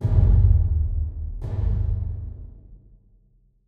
Big Drum Hit 31.wav